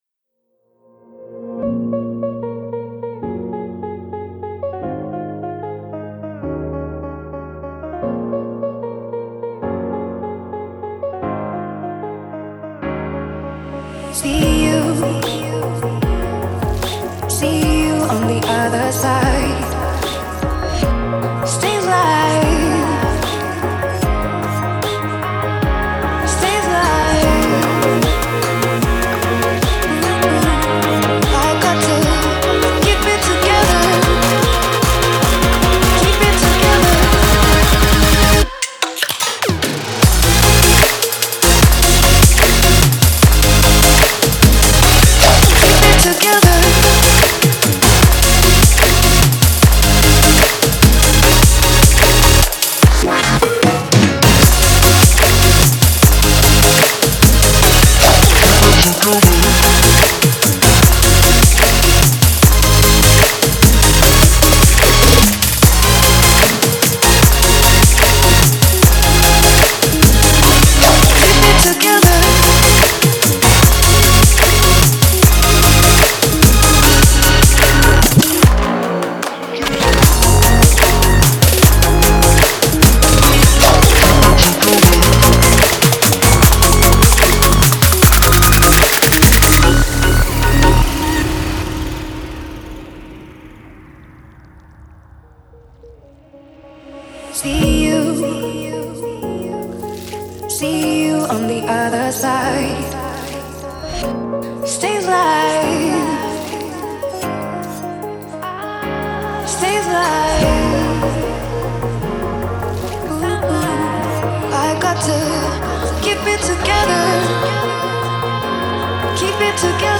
Electronic, Gloomy, Mysterious, Suspense, Weird